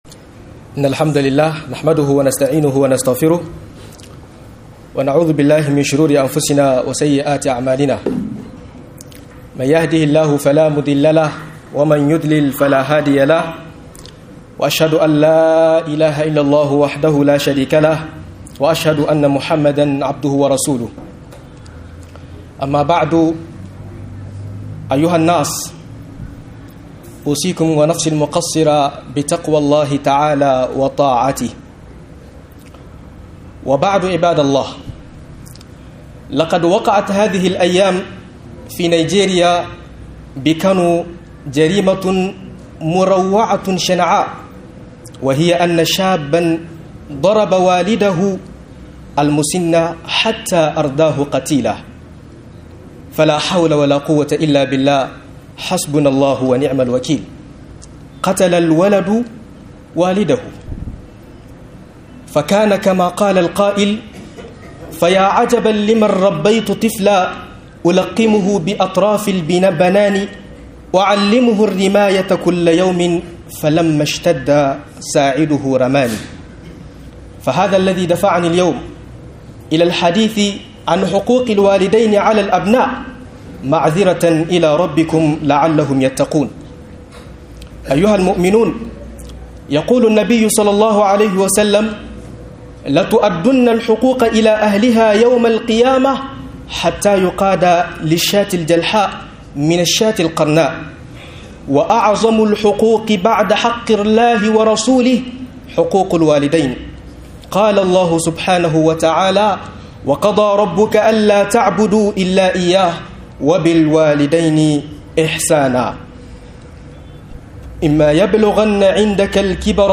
Yaro ya kache mahayfin sa - MUHADARA